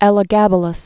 (ĕlə-găbə-ləs)